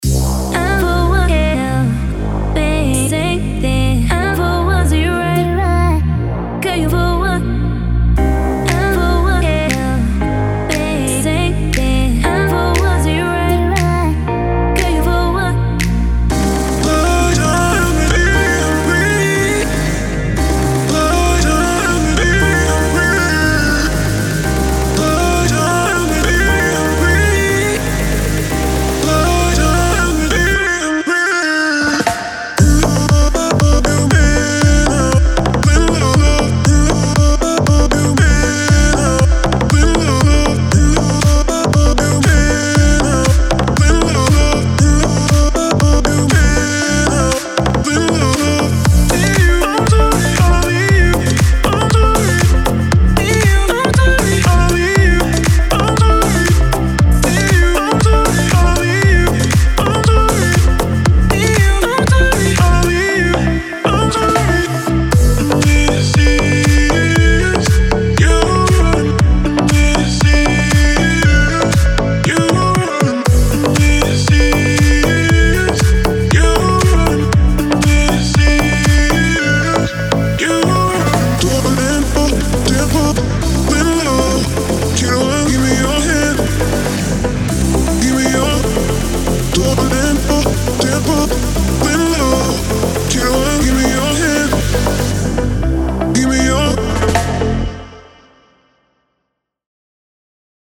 请注意：此包装中不包含除声乐排骨以外的所有其他乐器。“
– 100 Loops (50 Wet & 50 Dry)